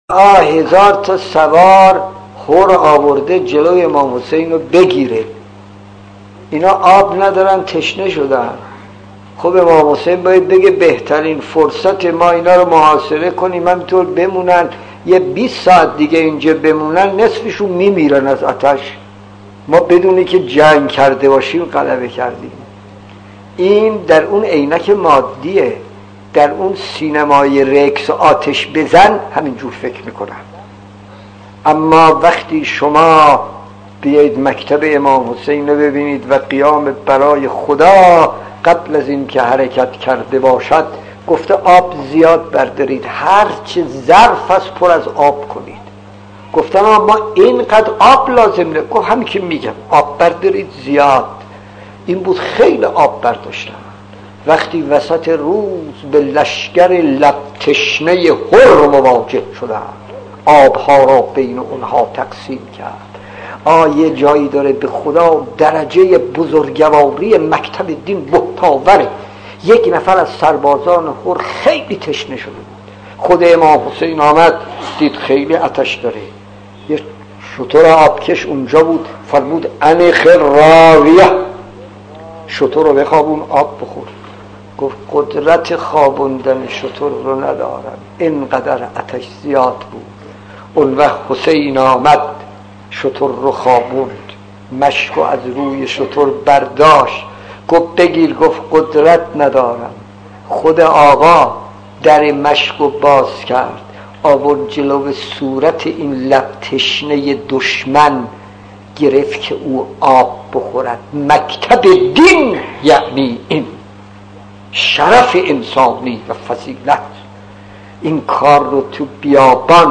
داستان 16 : امام حسین و حر خطیب: استاد فلسفی مدت زمان: 00:02:13